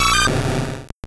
Cri de Kaiminus dans Pokémon Or et Argent.